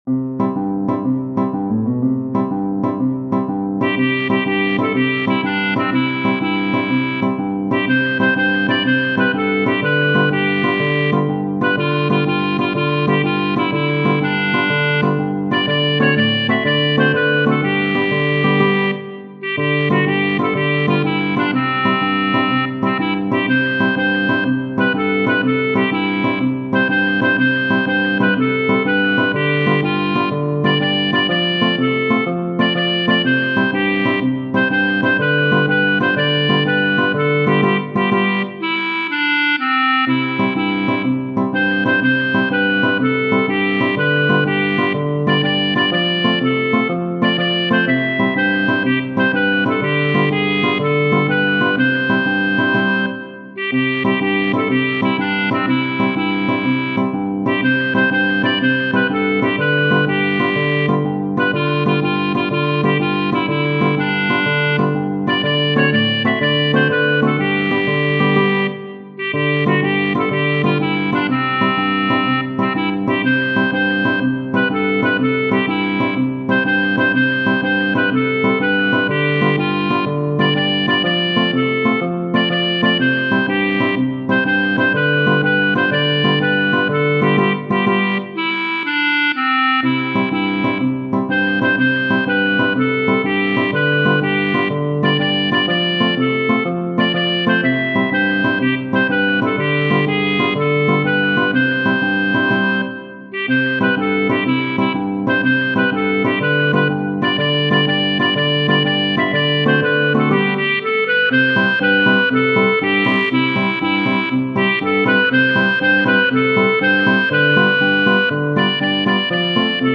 Tradizionale Genere: Folk Testo di anonimo Fatece largo che passamo noi, i giovanotti de 'sta Roma bella, semo regazzi fatti cor pennello e le ragazze famo innamorà. e le ragazze famo innamorà.